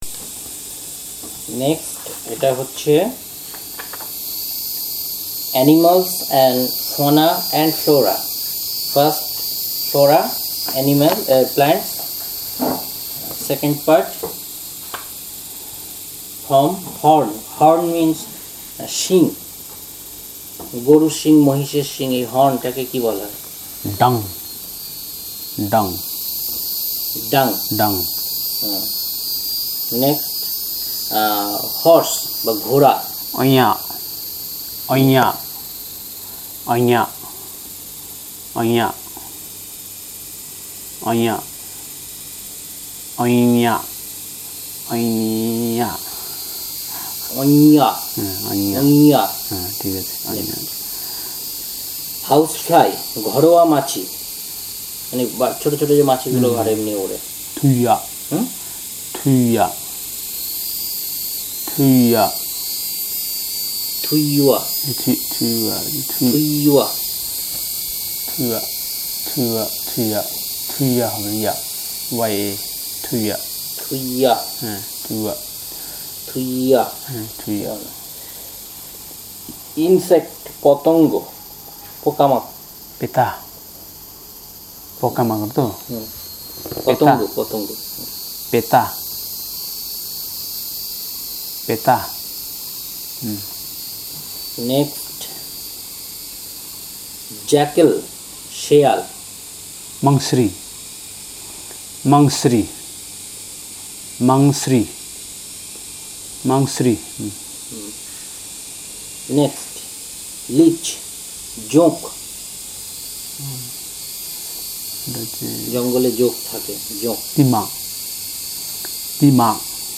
Elicitation of words about animals